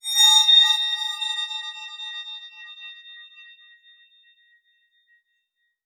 metallic_glimmer_drone_06.wav